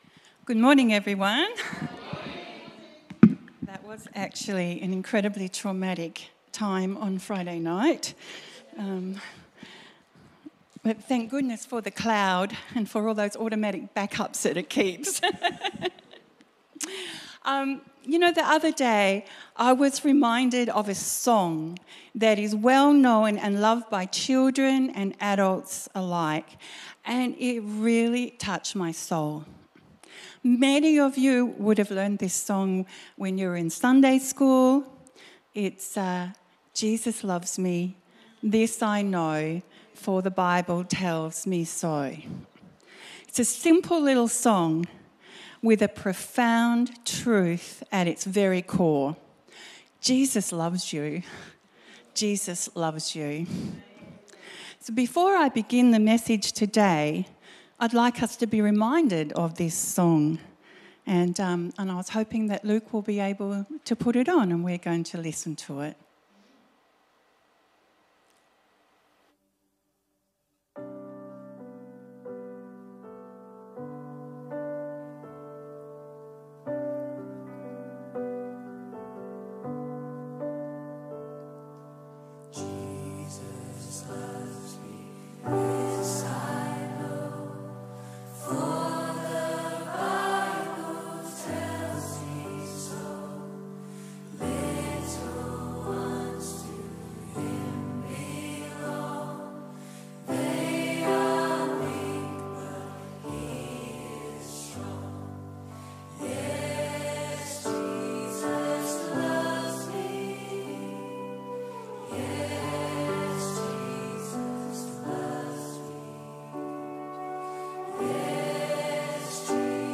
Jesus Loves Me on YouTube was watched during the introduction to the sermon.